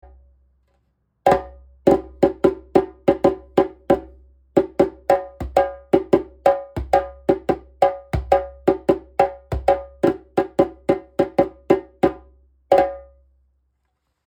ボディ インドネシア 打面 33cm、高さ64cm、重量55kg 木材 マホガニー 皮 ヤギ皮 マリ やや厚め
baliDjembe2.mp3